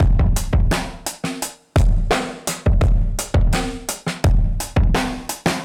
Index of /musicradar/dusty-funk-samples/Beats/85bpm/Alt Sound
DF_BeatB[dustier]_85-01.wav